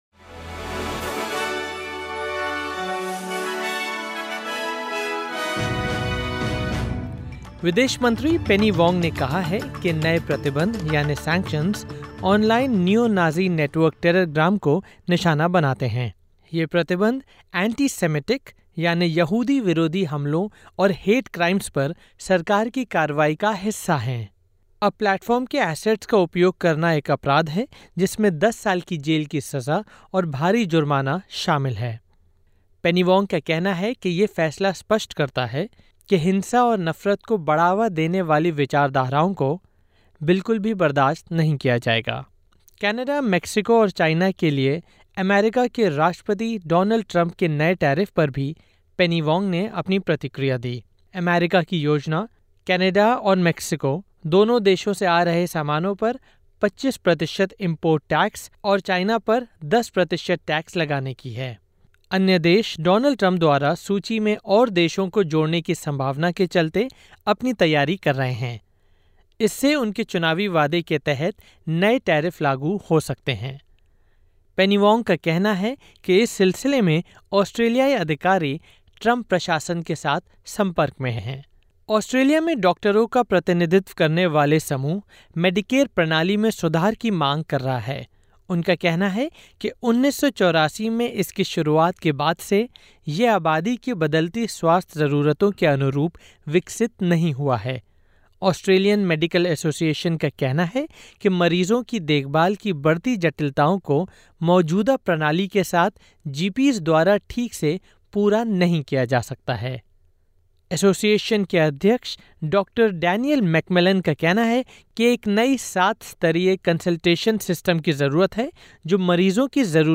सुनें ऑस्ट्रेलिया और भारत से 03/02/2025 की प्रमुख खबरें।